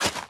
sounds / item / hoe / till1.ogg
till1.ogg